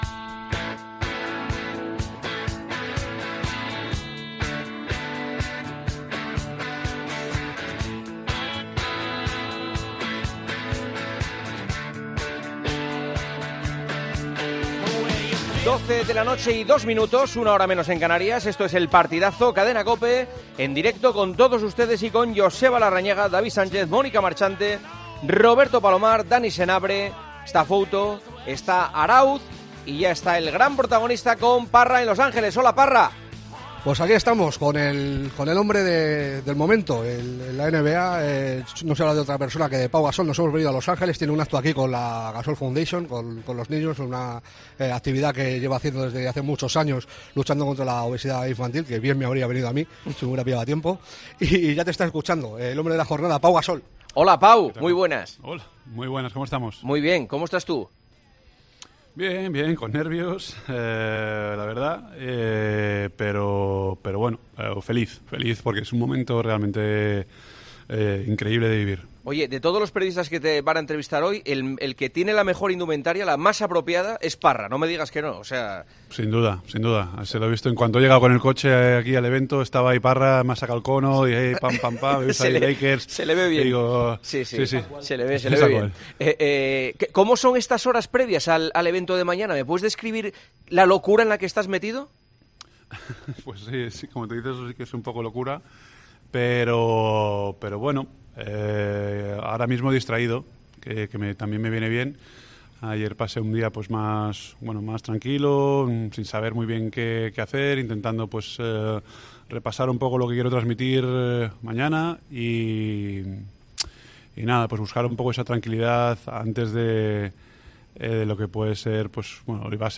Juanma Castaño habla en El Partidazo de COPE con Pau Gasol el día antes de que entre en la historia de Los Lakers, que retirarán su camiseta con el dorsal número 16.
Juanma Castaño entrevista a Pau Gasol, un día antes de la retirada de su dorsal número 16 de Los Angeles Lakers.